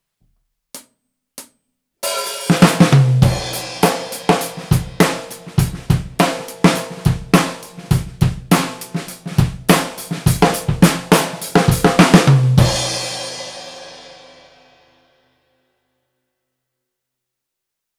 すべて、EQはしていません。
お次は、スタンダードなマイキングで録ってみたいと思います。
全体が非常にバランス良く録れていますね！